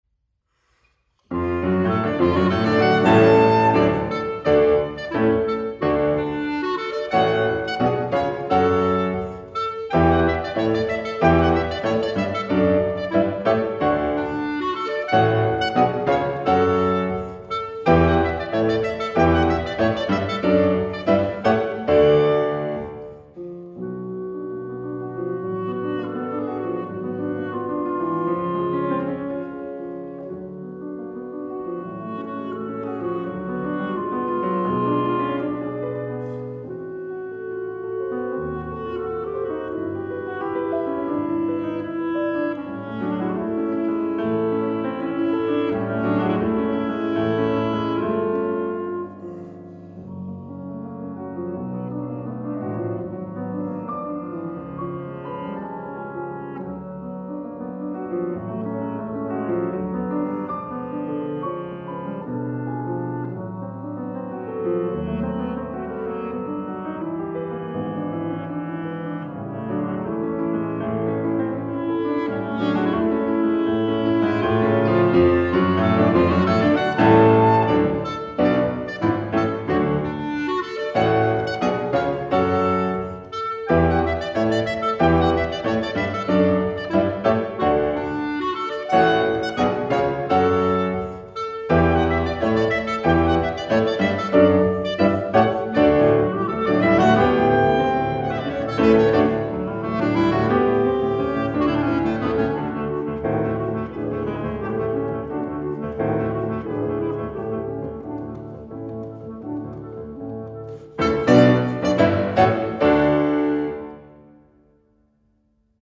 Sonate für Klarinette und Klavier
Klarinette: Schwenk & Seggelke
Flügel: Steinwas & Sons D